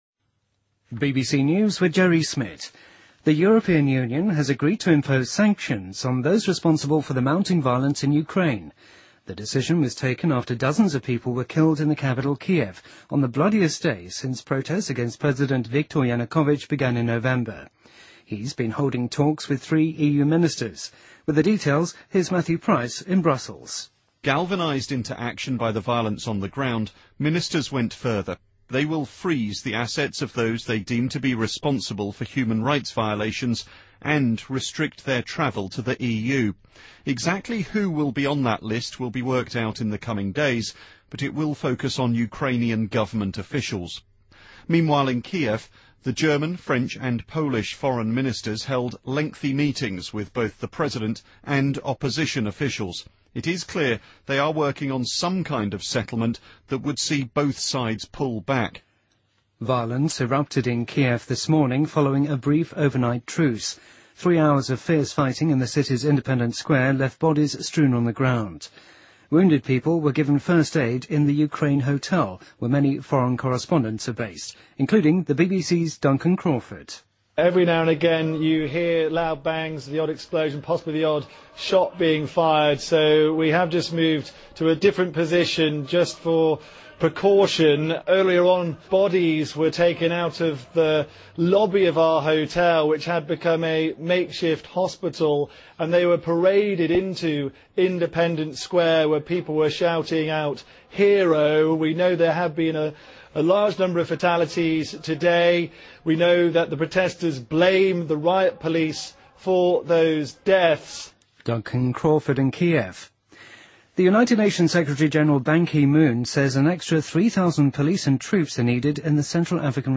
BBC news,2014-02-21